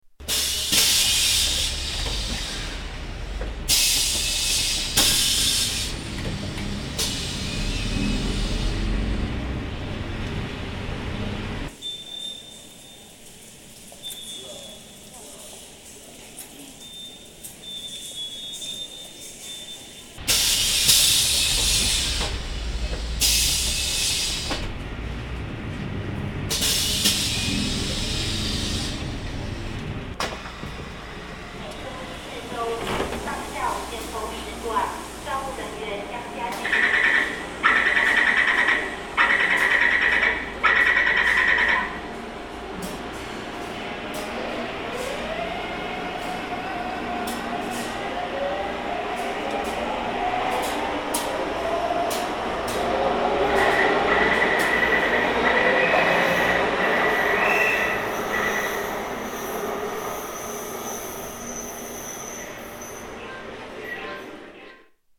SanChung to MRT sounds